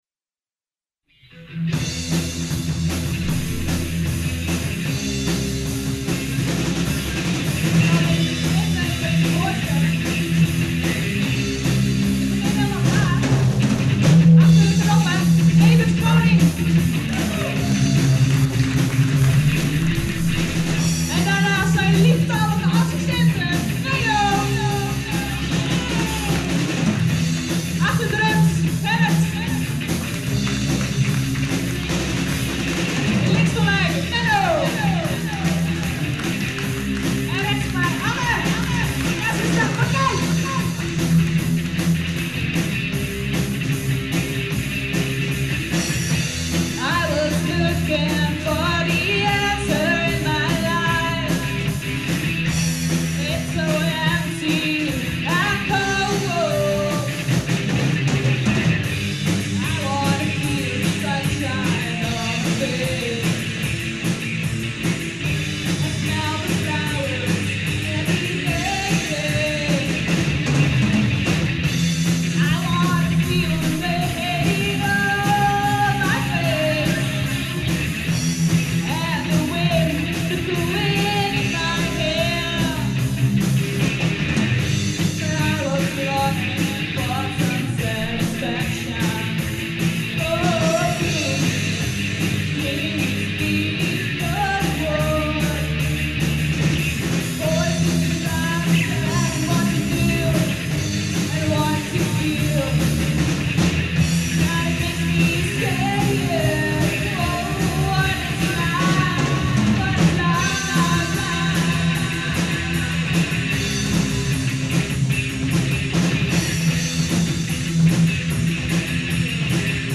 Gospelrockband Ignition (1990 - 1996) Het eerste vaste bandje waar ik in kwam was gospelrockband Ignition. Een bandje waarmee we eigenlijk door heel Nederland heen speelden met (zoals de naam al doet vermoeden) 'stevige muziek met een boodschap'.
gitaar/zang
bas/zang
en dan natuurlijk ondergetekende op drums.
Ondanks dat we in die tijd alleen op cassettebandjes op konden nemen heb ik het voor elkaar gekregen om een aantal opnames te digitaliseren: